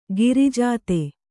♪ girijāte